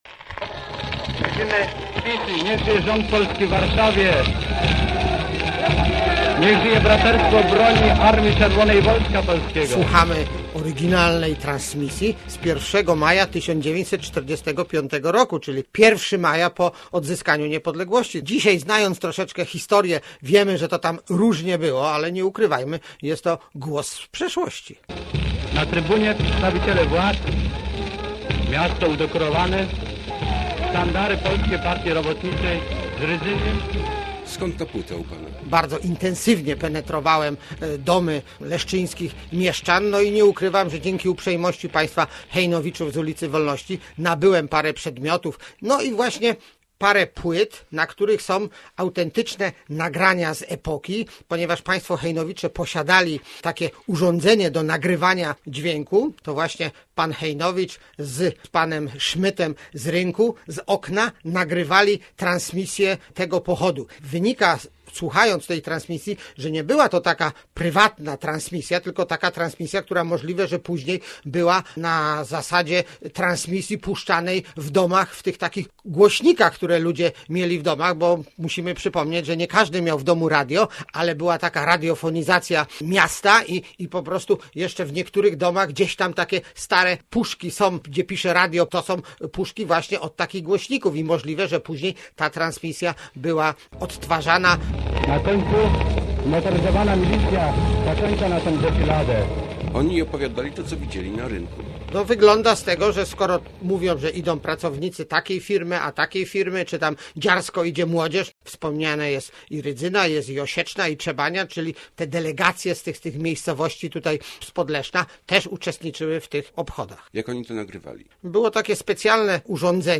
Dotarliśmy do niezwykle ciekawego "głosu z przeszłości", który został zapisany na płycie. To relacja z pochodu, który odbył się w Lesznie 1 maja 1945 roku. Historia całkiem ciekawa, bo opisuje nie tylko pochód ale też to, jakie było Leszno kilka miesięcy po zakończeniu wojny.